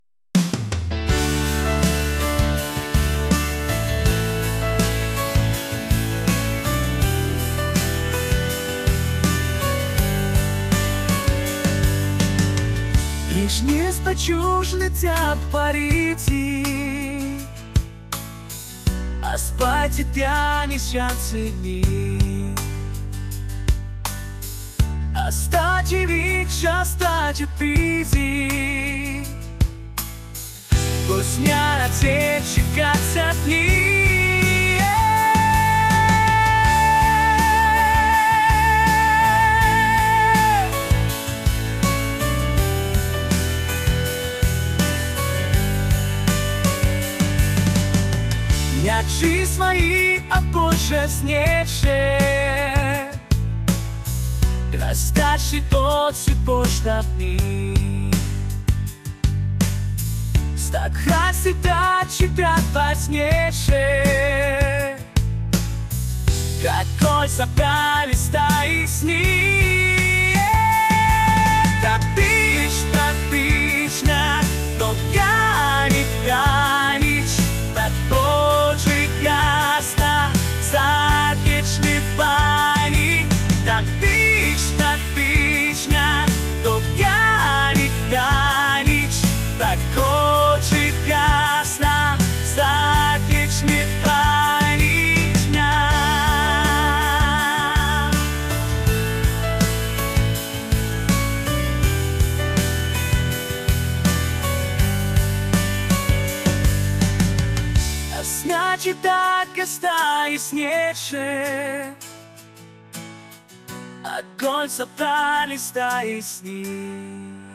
catchy | pop